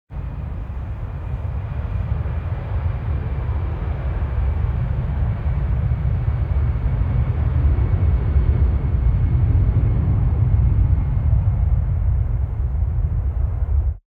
plane_fly_rumble_loop.ogg